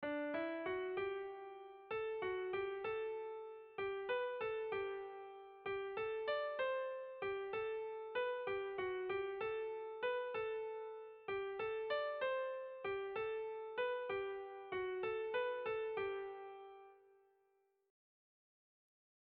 Seiko berezia, 3 puntuz (hg) / Hiru puntuko berezia (ip)
ABB